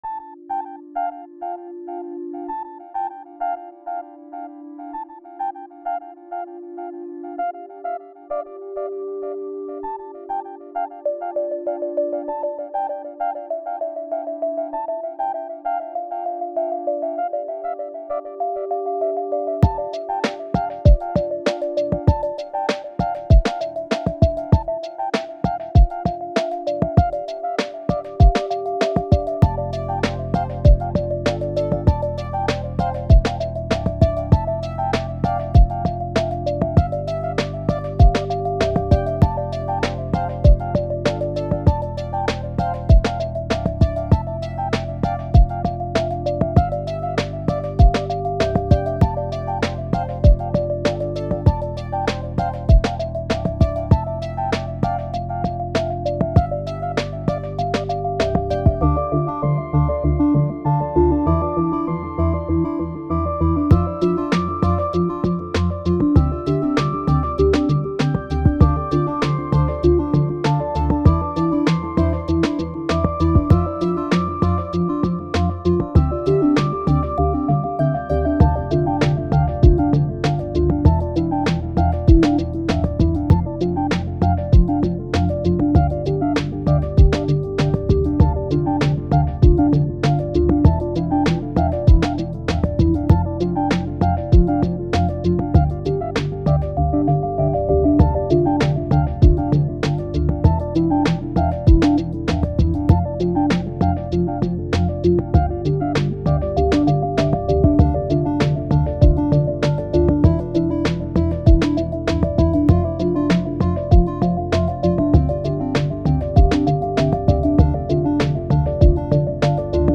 Short space theme song.